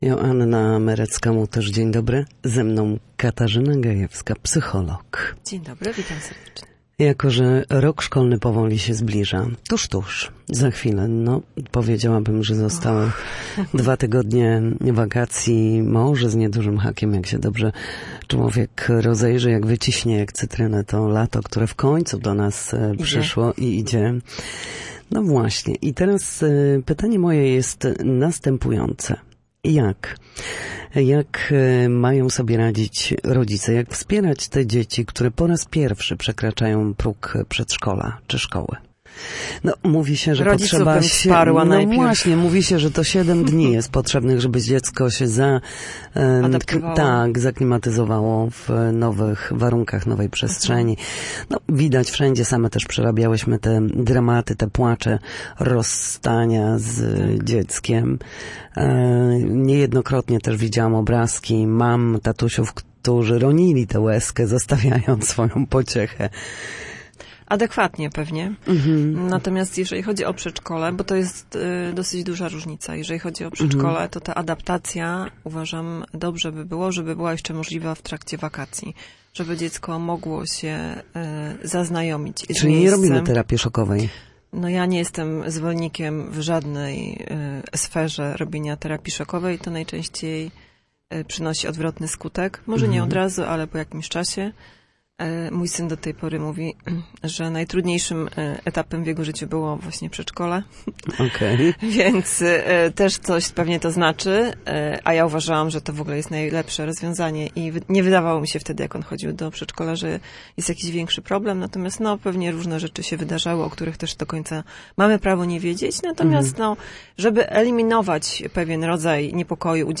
W każdą środę, w popołudniowym Studiu Słupsk Radia Gdańsk, dyskutujemy o tym, jak wrócić do formy po chorobach i urazach.